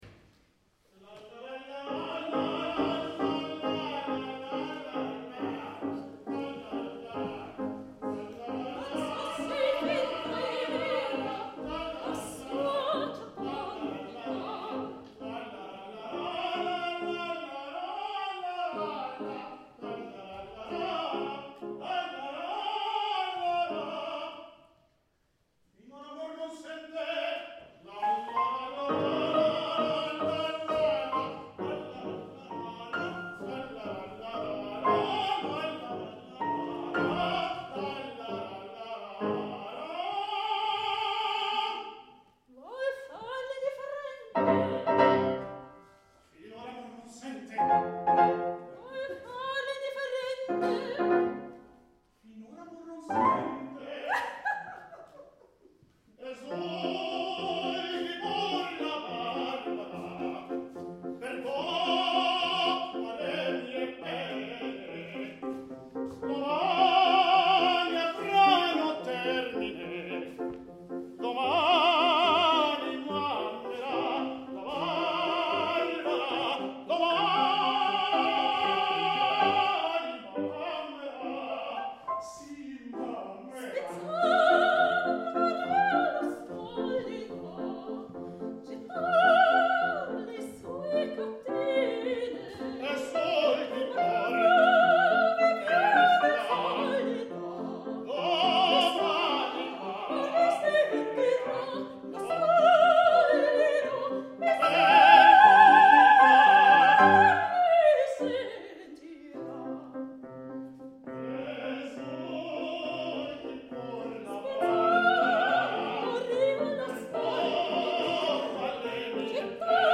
QUBA Quartierzentrum Bachletten
Arien und Duette aus Opern von Simone Mayr und
Sopran
Tenor
Klavier
Play      G. Donizetti, L'Elisir d'amoore, Duett Adina / Nemorino,
MUFA309a_Donizetti_Elisir_LaraLaLa_Duett.mp3